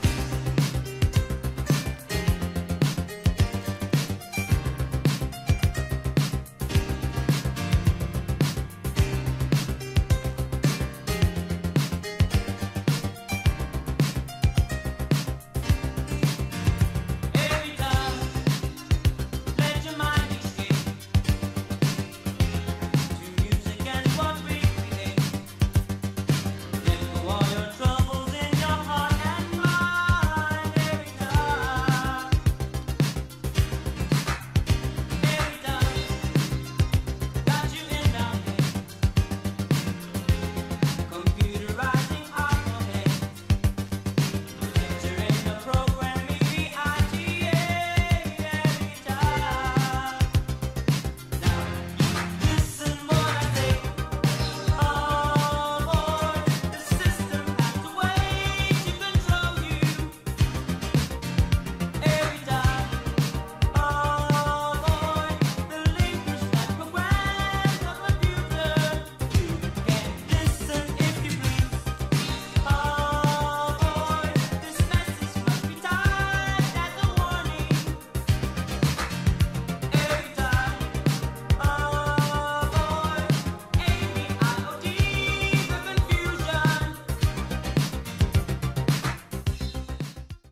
哀愁イタロ・ディスコ・クラシックの極めつき！！